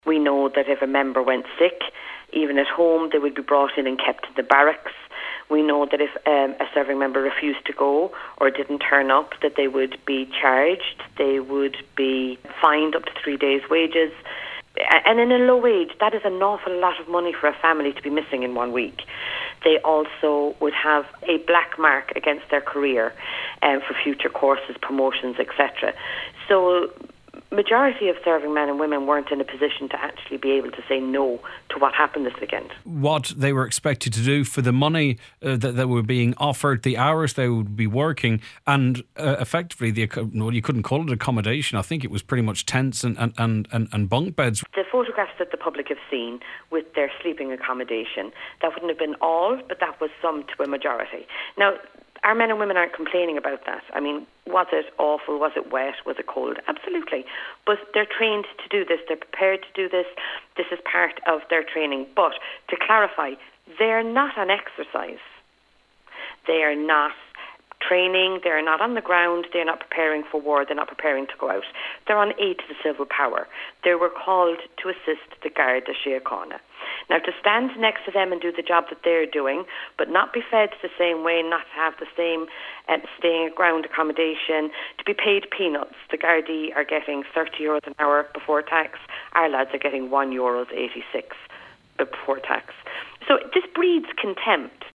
On today’s Nine til Noon Show, she said soldiers had no choice this weekend, and those who refused to carry out assigned duties faced serious consequences: